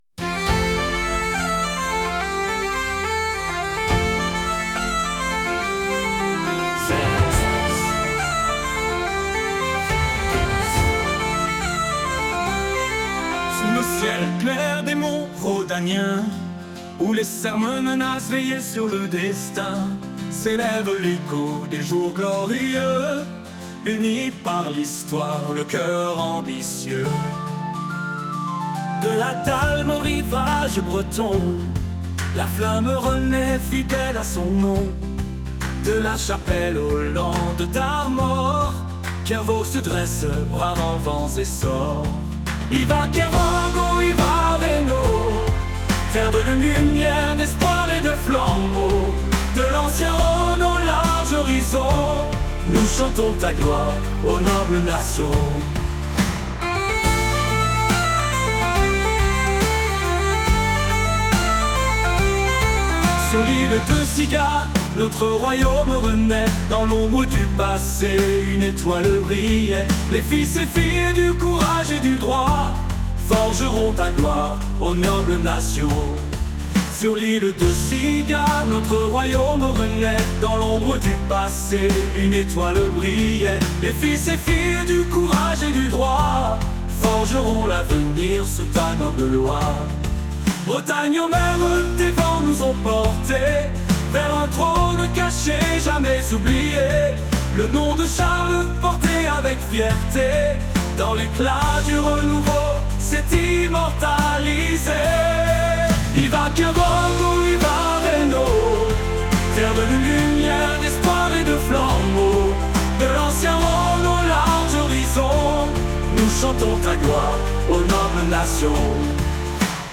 Choeurs de Kervog intèrprétant Yva Kervog o Yva Réno